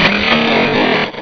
Cri de Libégon dans Pokémon Rubis et Saphir.